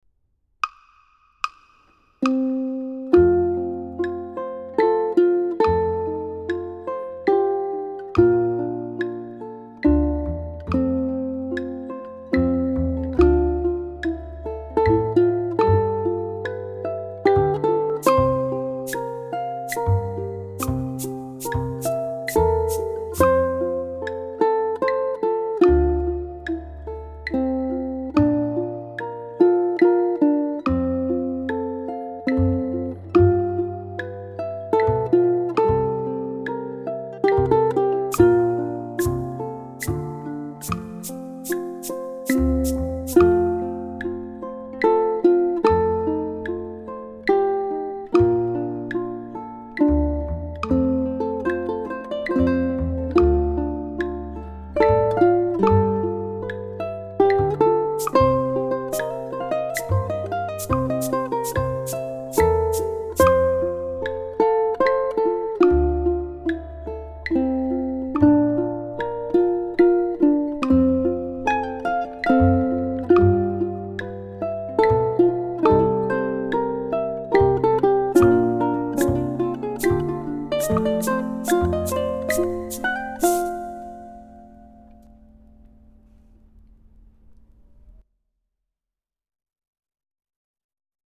This four-phrase song is in the key of F major—the quintessential ʻukulele key—and, thus, carries a B-flat in the key signature.
Amazing Grace was recorded on Kremona Coco and Mari tenor ukuleles. The melody was played plainly, but a few improvised blues licks were added during the repeat. Bass, guiro, cabasa and a Nino tone block are heard in the backing tracks.
ʻukulele